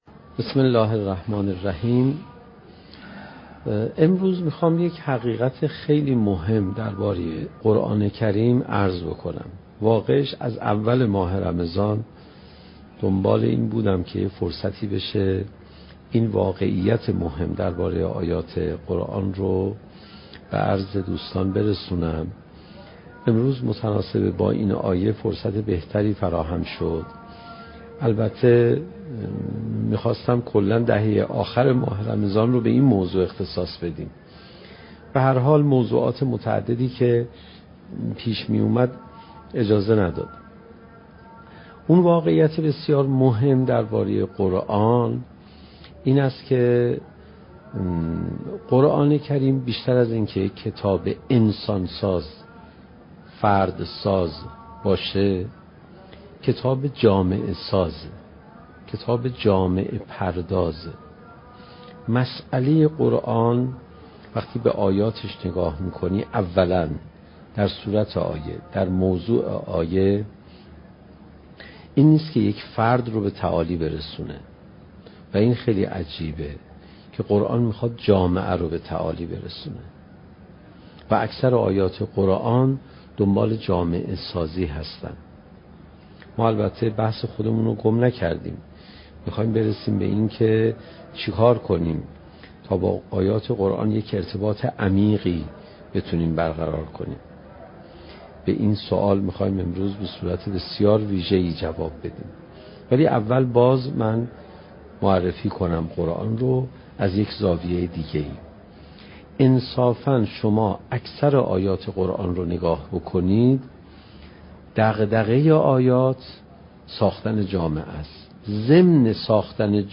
سخنرانی حجت الاسلام علیرضا پناهیان با موضوع "چگونه بهتر قرآن بخوانیم؟"؛ جلسه بیست و سوم: "تعالی جامعه با قرآن"